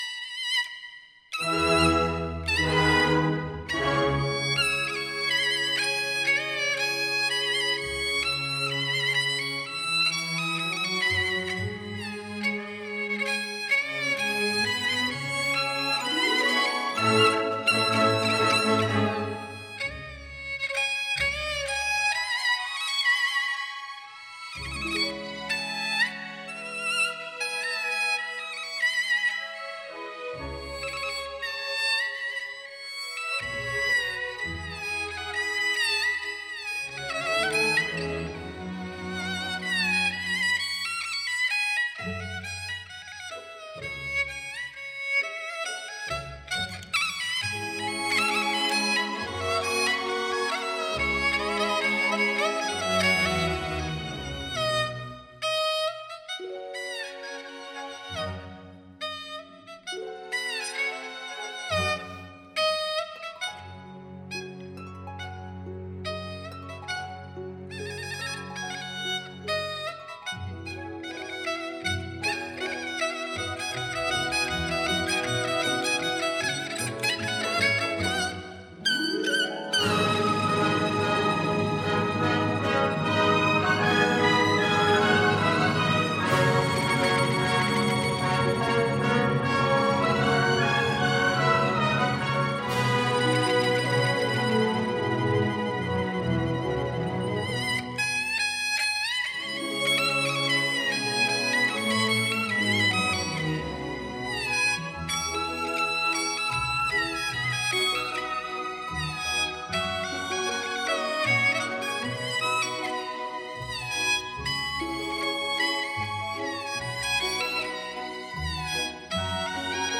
二胡独奏